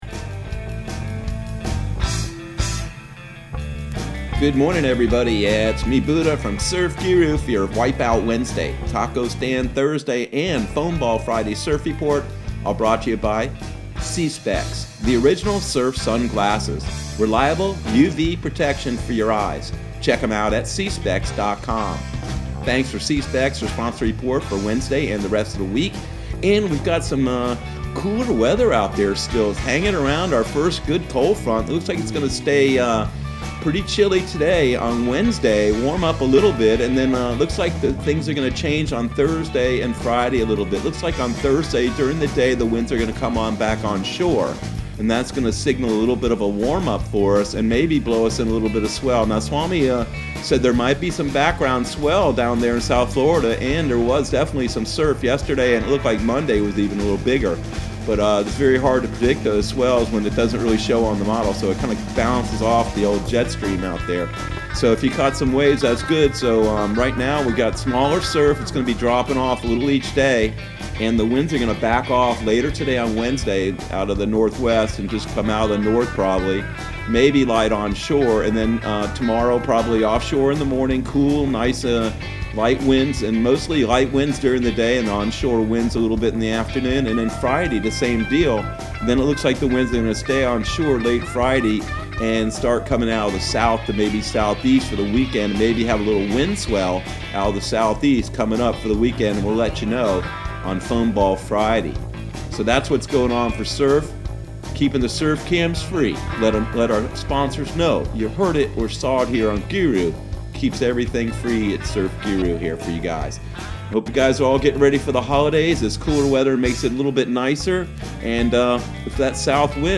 Surf Guru Surf Report and Forecast 12/09/2020 Audio surf report and surf forecast on December 09 for Central Florida and the Southeast.